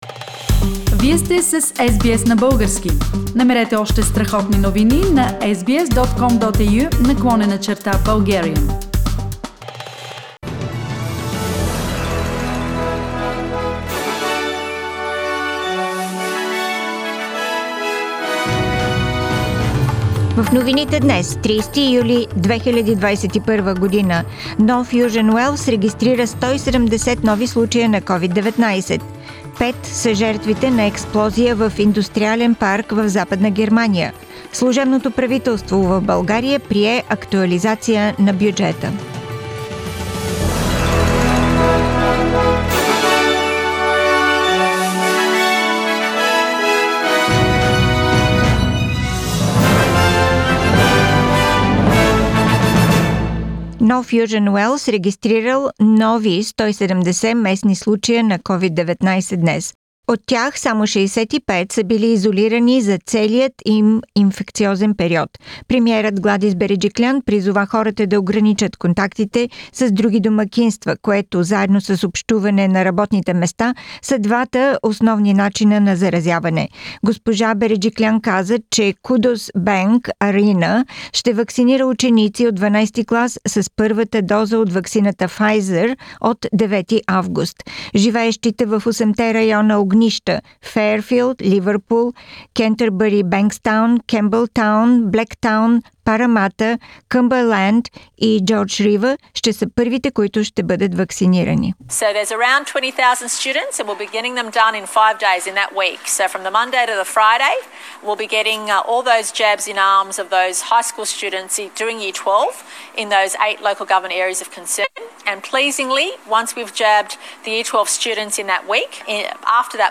Weekly Bulgarian News – 30th July 2021